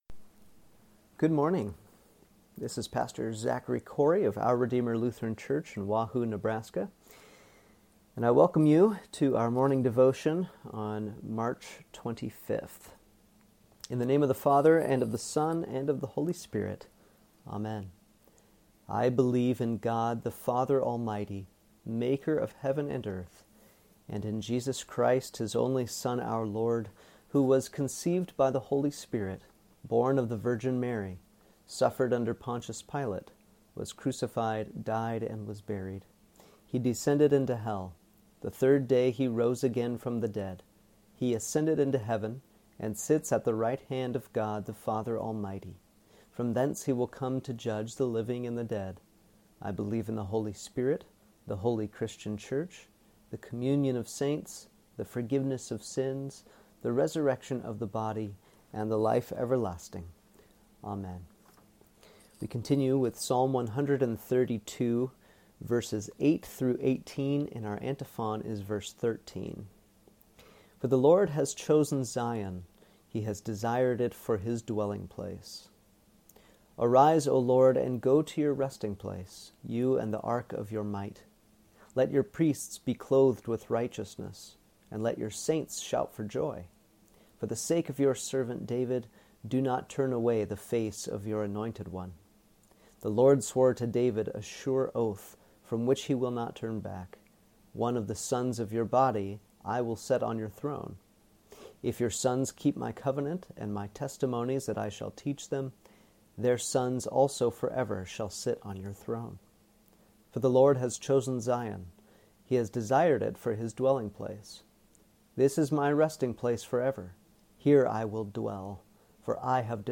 Morning Devotion for Wednesday, March 25th
Here’s Wednesday’s morning devotion.